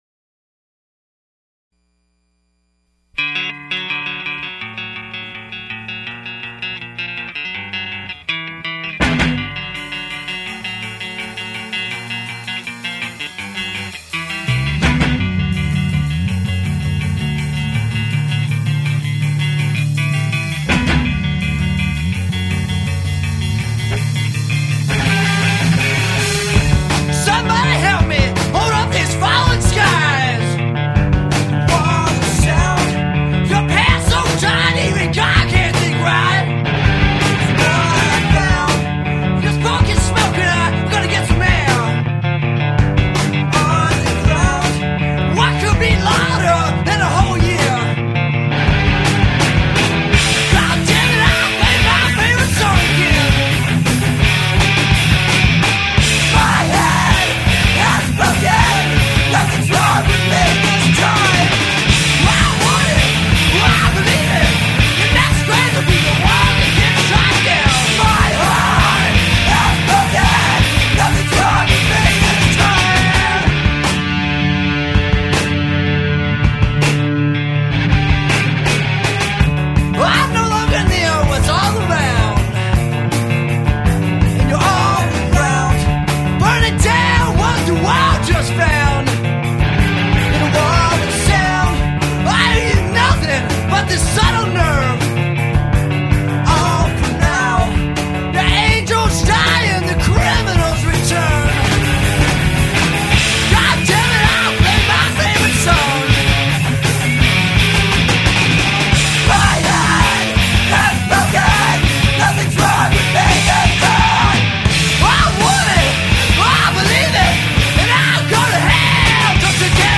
What comes out is probably best described as dark pop.